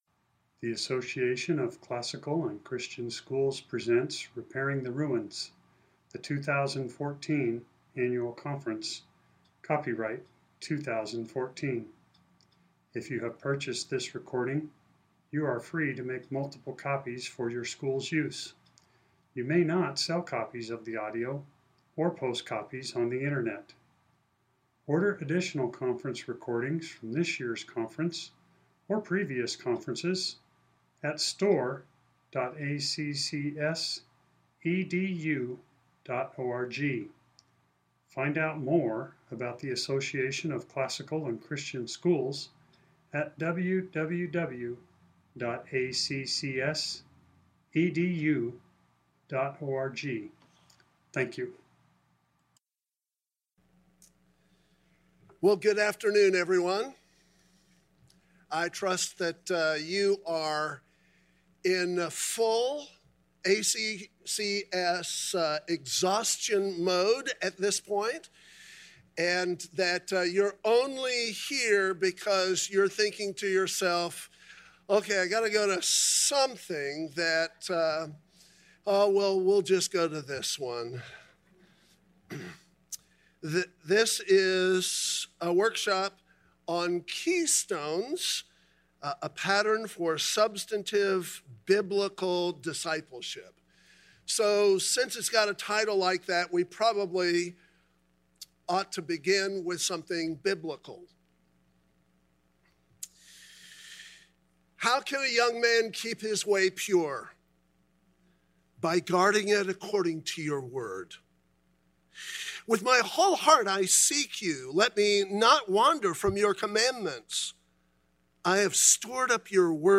2014 Workshop Talk | 0:58:15 | All Grade Levels, Theology & Bible
Additional Materials The Association of Classical & Christian Schools presents Repairing the Ruins, the ACCS annual conference, copyright ACCS.